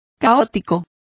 Complete with pronunciation of the translation of chaotic.